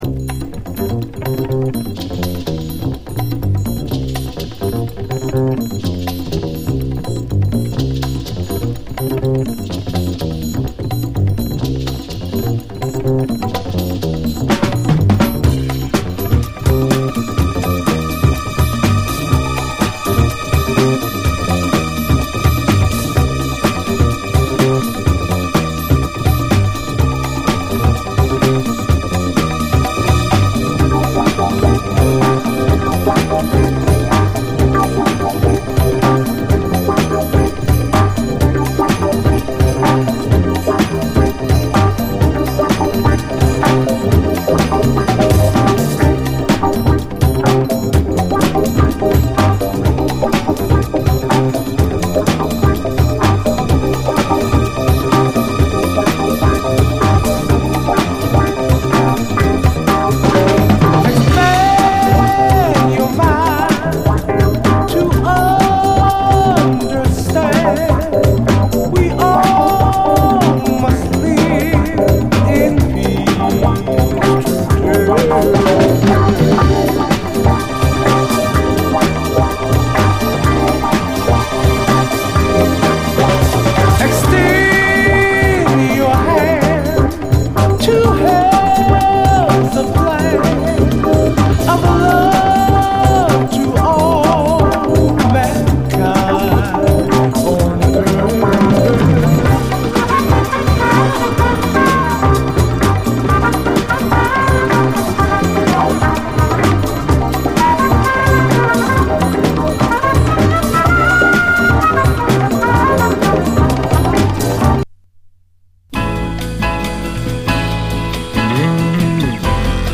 JAZZ FUNK / SOUL JAZZ, JAZZ
シンセ＆エレピがスペイシーに響く名曲揃い！